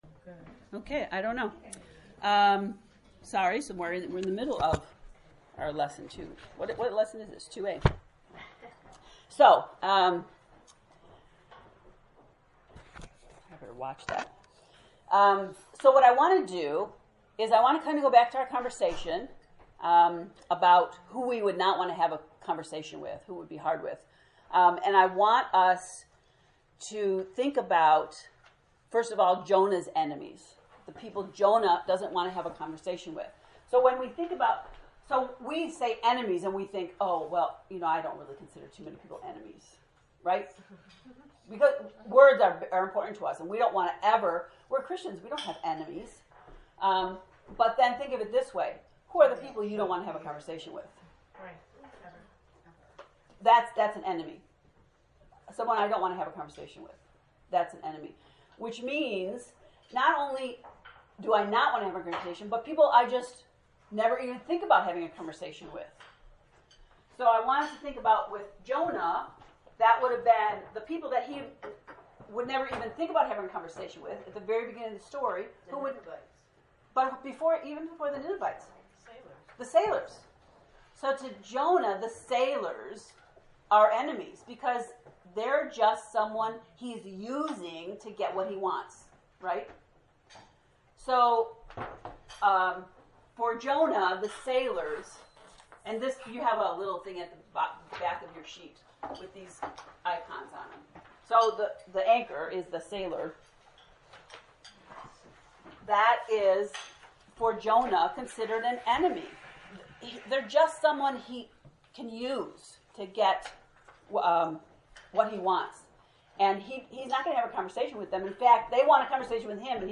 jonah-lect-2a.mp3